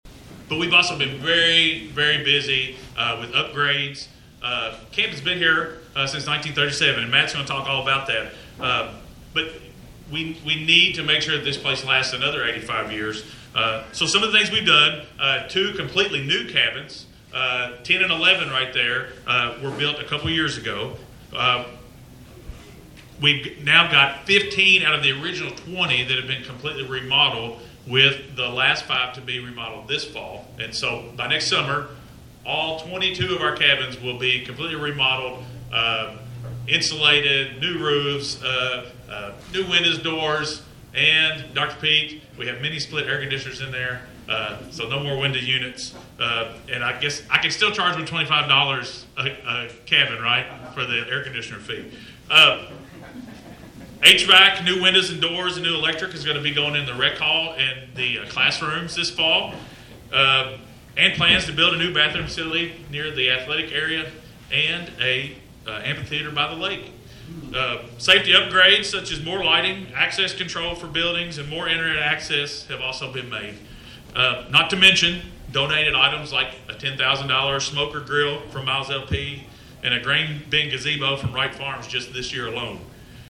On July 18th, the camp held a groundbreaking ceremony for a new activity center.